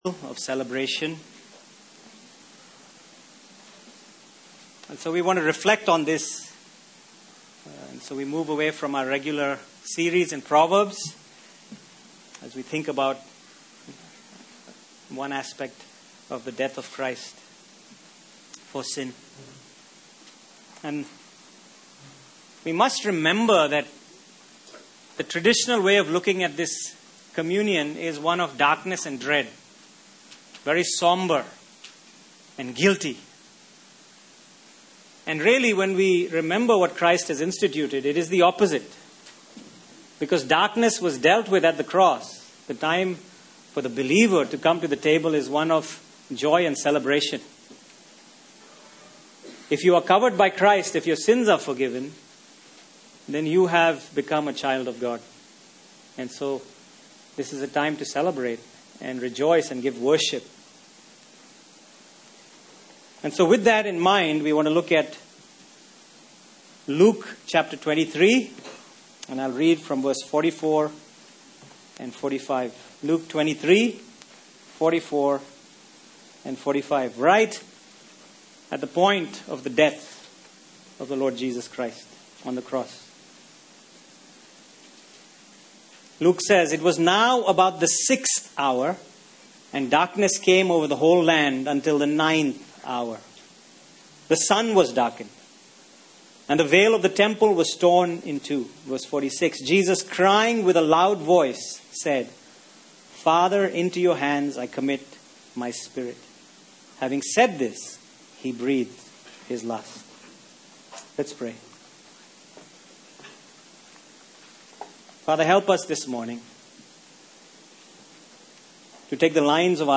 Passage: Luke 23:44-45 Service Type: Sunday Morning